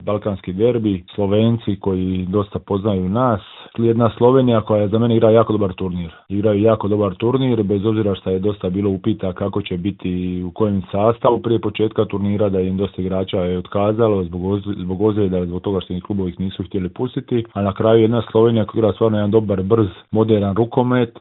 Za Media servis je svoje dojmove podijelio nekadašnji reprezentativac i "ministar obrane" te aktualni kapetan Zagreba Jakov Gojun.